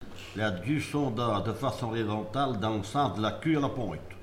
Il aiguise la faux ( selon l'une des trois façons d'aiguiser )
Saint-Jean-de-Monts